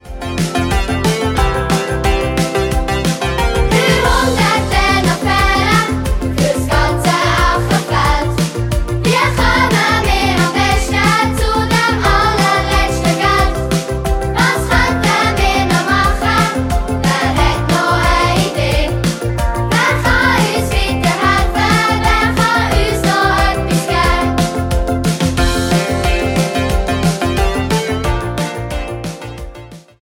Musical-Album